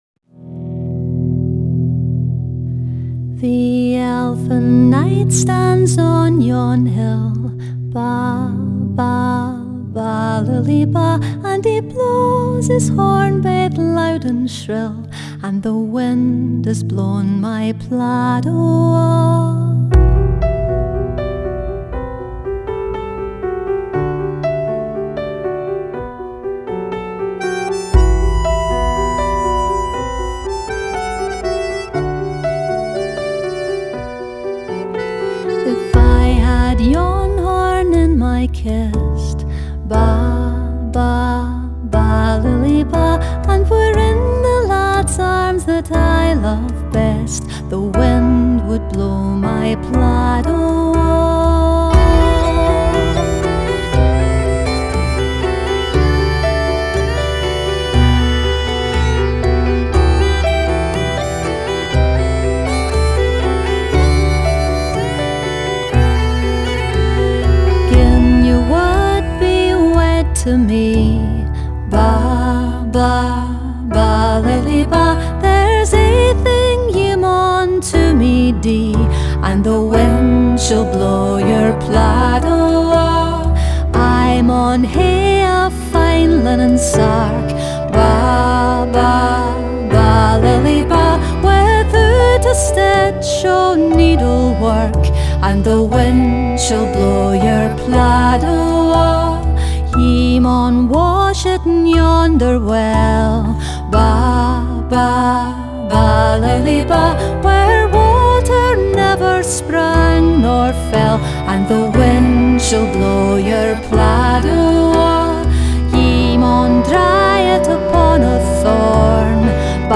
Genre: World, Folk, Celtic, Contemporary Celtic